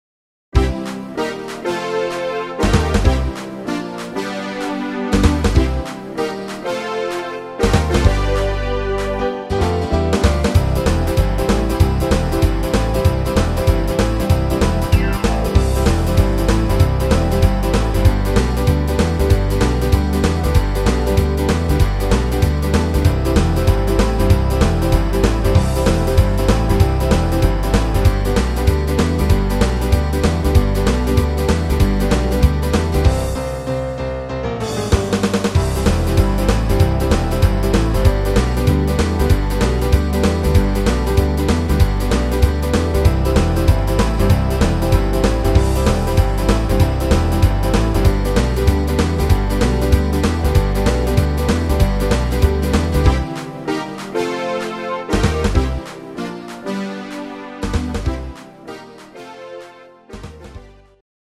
instrumental Saxophon